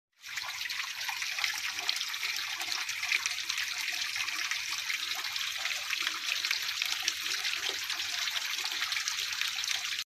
Heavy Rain And Thunderstorm Sounds sound effects free download